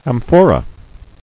[am·pho·ra]